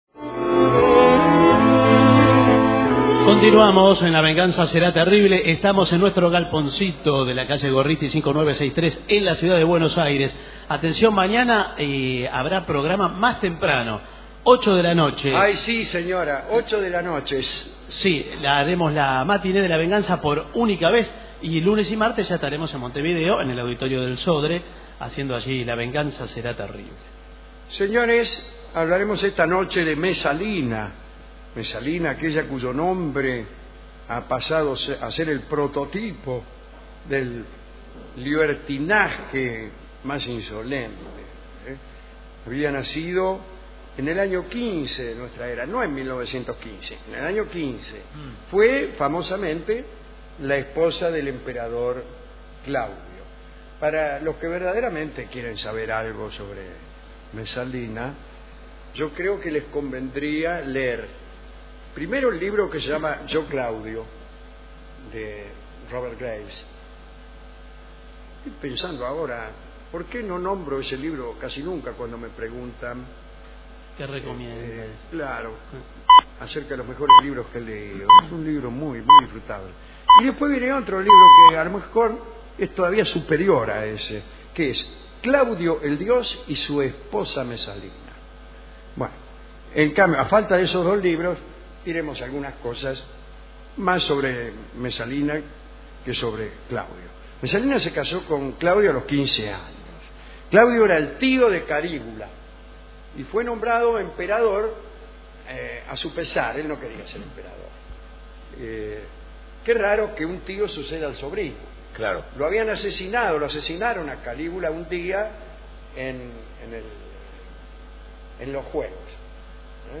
Estudios de Radio El Mundo (AM 1070 kHz), 1987